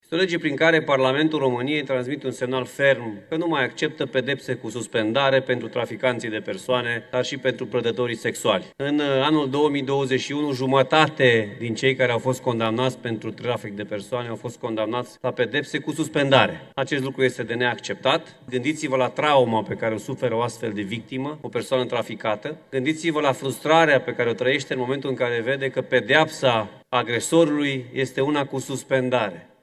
Alfred Simonis, președintele interimar al Camerei Deputaților: „În anul 2021, jumătate din cei care au fost condamnați pentru trafic de persoane au fost condamnați la pedeapsa cu suspendare”